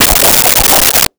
Swirl 01
Swirl 01.wav